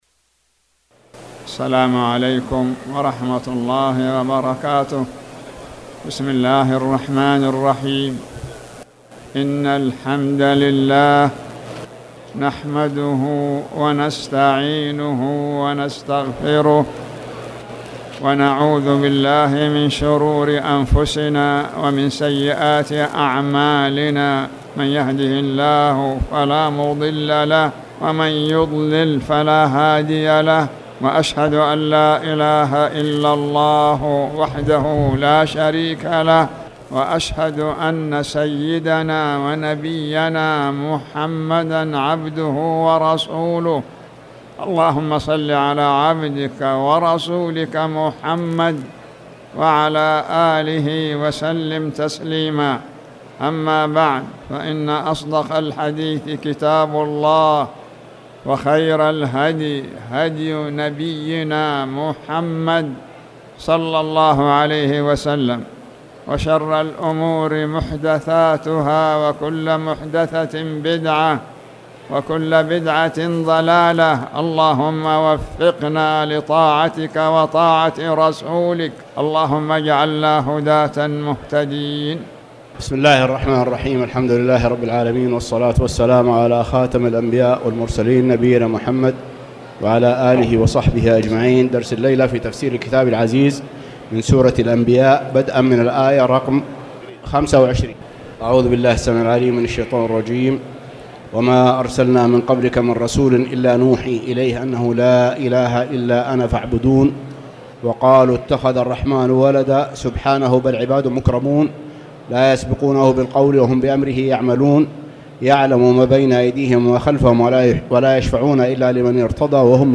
تاريخ النشر ٨ ذو القعدة ١٤٣٨ هـ المكان: المسجد الحرام الشيخ